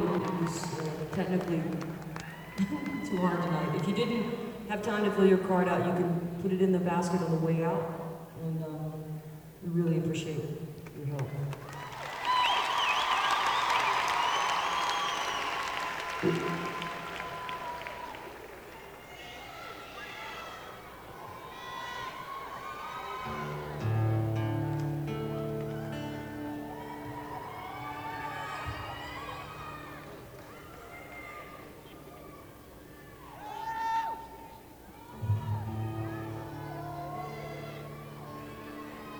lifeblood: bootlegs: 1997-09-10: the palace theater - albany, new york (honor the earth)